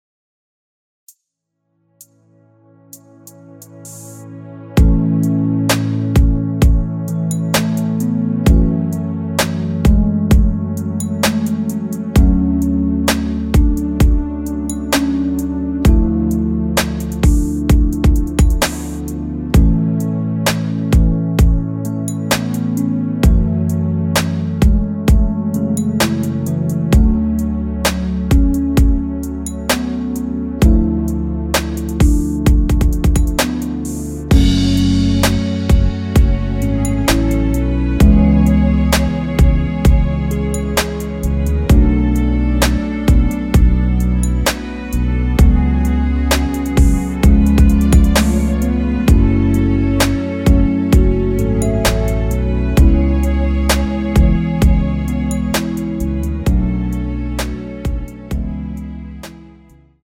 원키에서(-2)내린 MR 입니다.
앞부분30초, 뒷부분30초씩 편집해서 올려 드리고 있습니다.